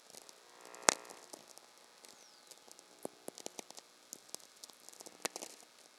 Archived Whistler Event Data for 2024-05-12 Forest, VA USA